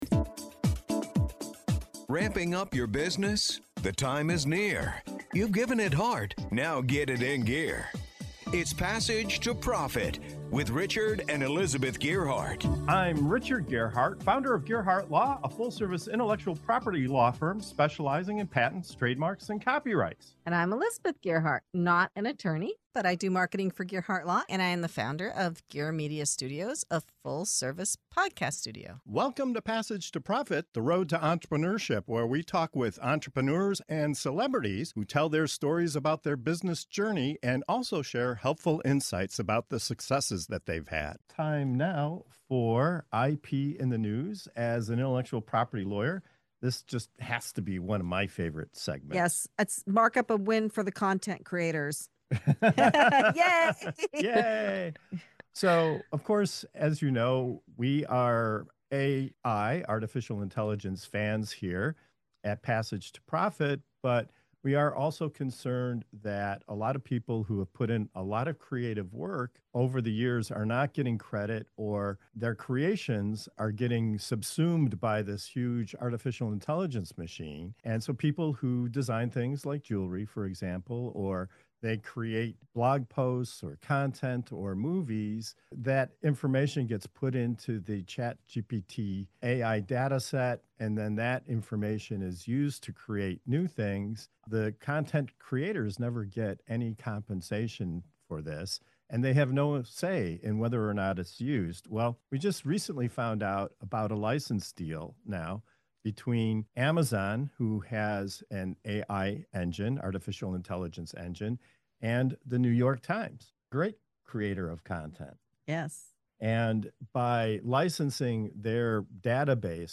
In this segment of Your New Business Journey on Passage to Profit Show, we dive into the hot topic of intellectual property in the age of AI. With Amazon striking a licensing deal with The New York Times, is this the start of a new era where content creators finally get paid for feeding the AI machine? Our panel unpacks what this means for artists, writers, inventors—and anyone whose work could be swept into AI datasets without credit or compensation.